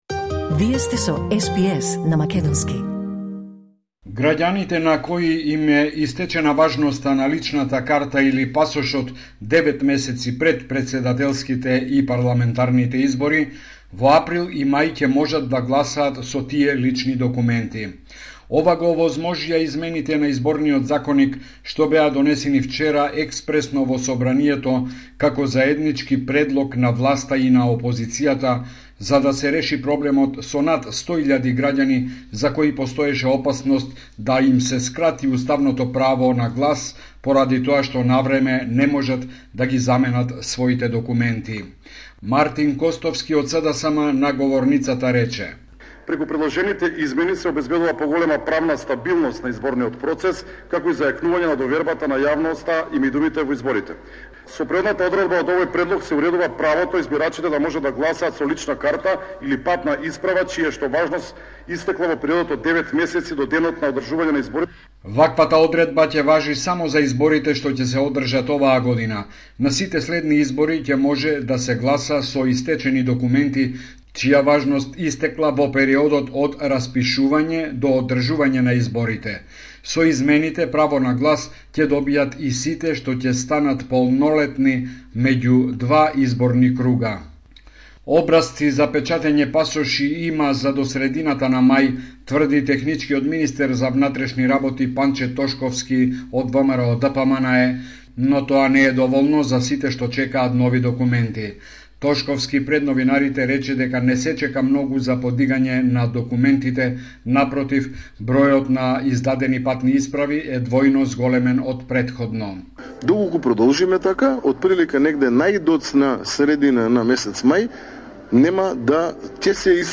Homeland Report in Macedonian 8 March 2024